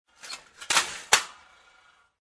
Descarga de Sonidos mp3 Gratis: caida 8.